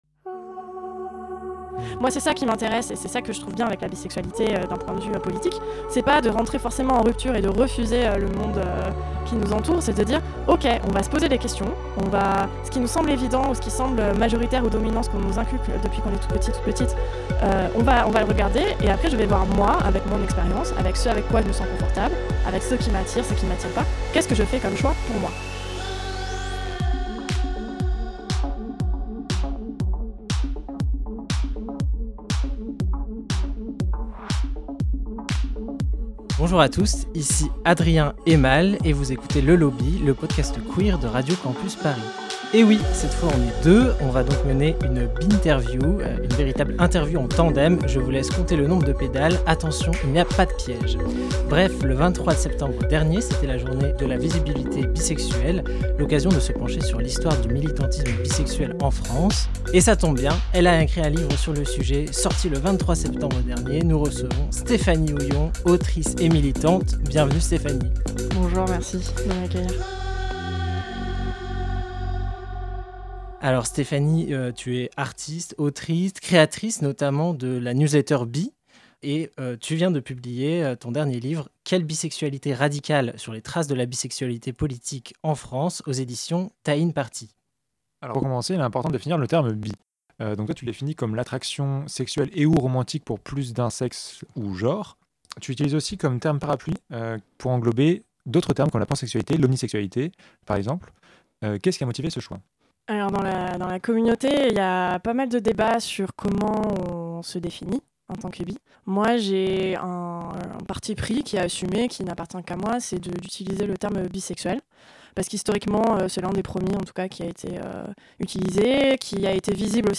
Nous avons rencontré quelques problèmes techniques lors de l'enregistrement de cet épisode, le voici donc retranscrit ci-dessous !